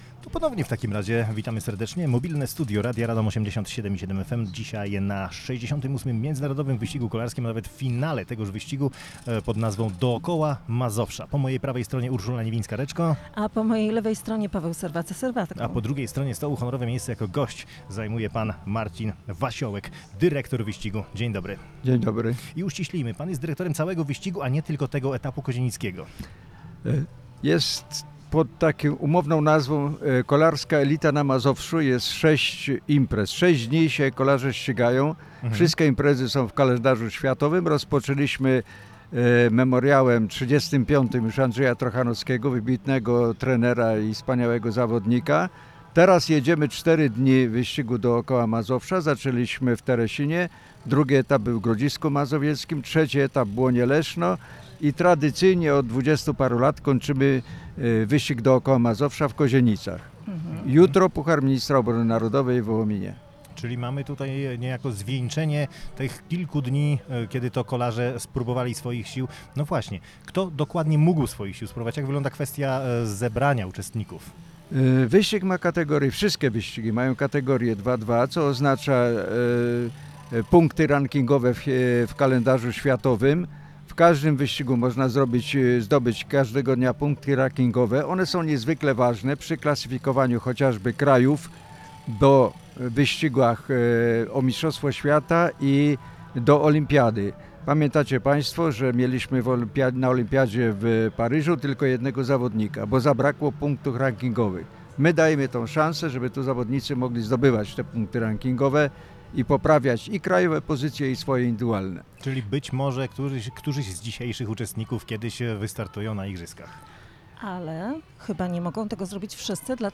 W Kozienicach trwa ostatni etap 68. Międzynarodowego Wyścigu Kolarskiego „Dookoła Mazowsza”. Na miejscu jest mobilne studio Radia Radom.